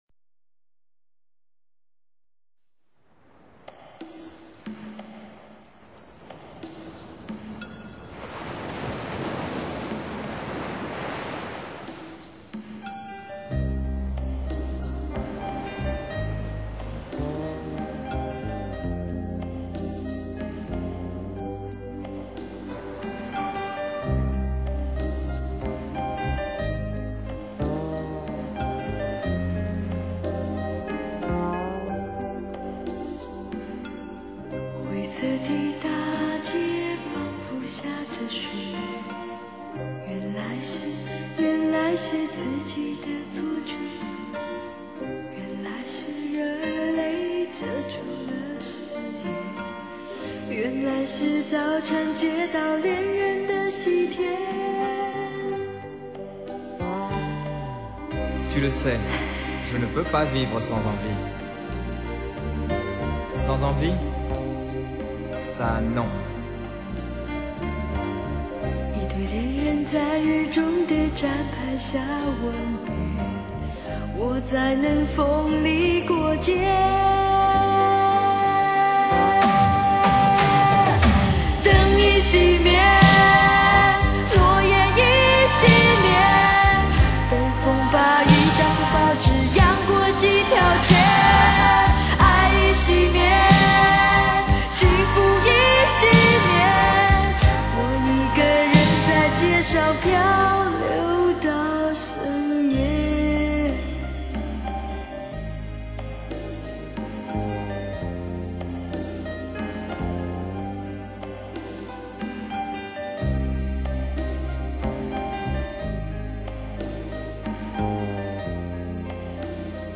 很有画面感的一首歌曲。